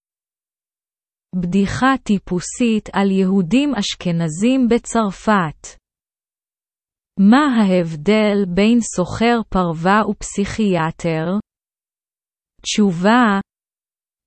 Texte Hébreu lu à haute voix à un rythme lent !